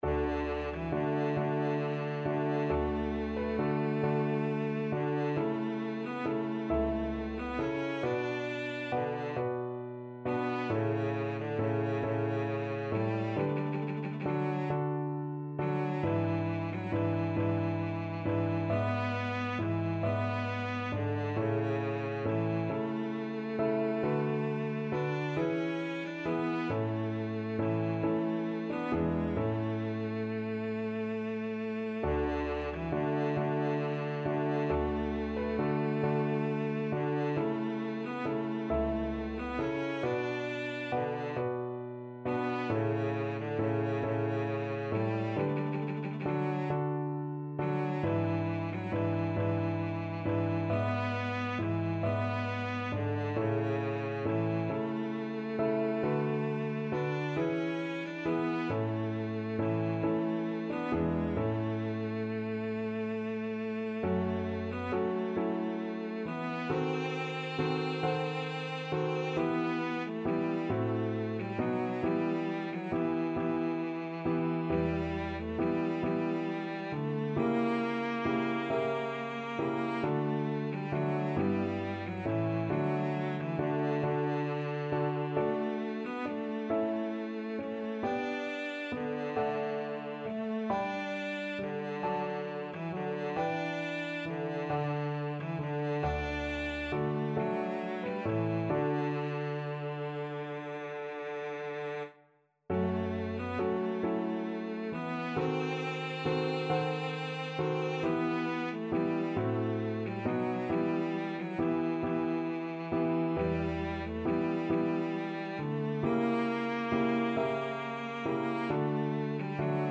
Free Sheet music for Cello
Cello
D major (Sounding Pitch) (View more D major Music for Cello )
12/8 (View more 12/8 Music)
II: Larghetto cantabile .=45
Classical (View more Classical Cello Music)